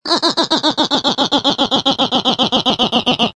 Descarga de Sonidos mp3 Gratis: risa 15.